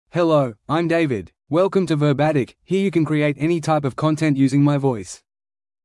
David — Male English (Australia) AI Voice | TTS, Voice Cloning & Video | Verbatik AI
MaleEnglish (Australia)
Voice sample
Male
David delivers clear pronunciation with authentic Australia English intonation, making your content sound professionally produced.